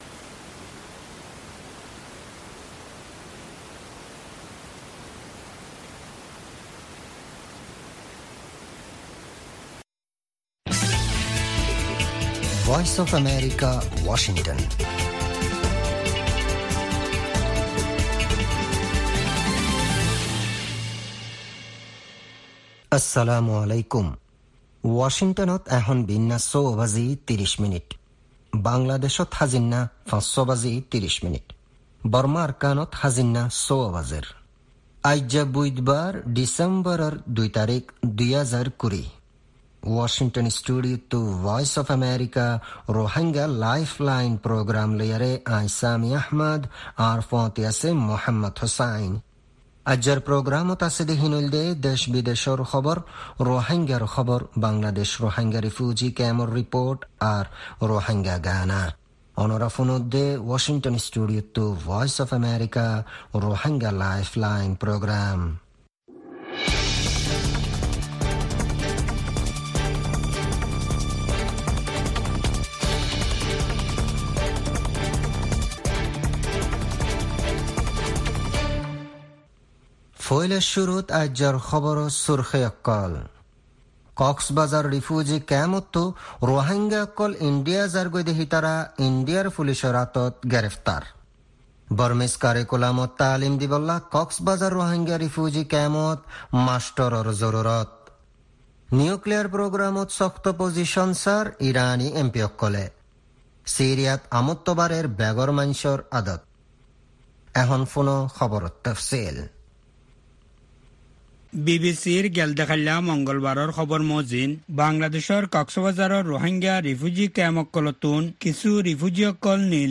Rohingya “Lifeline” radio
News Headlines